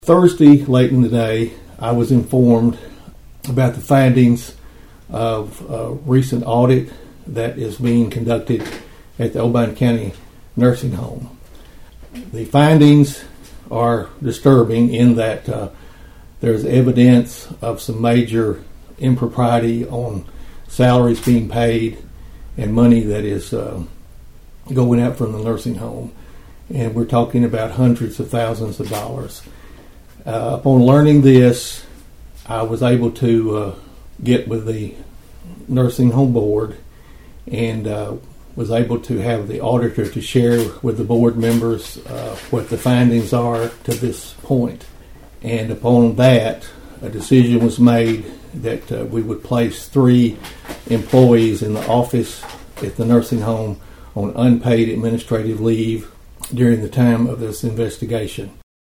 Mayor Carr made the announcement during a press conference held Sunday afternoon at the County Mayor’s office in Union City.(AUDIO)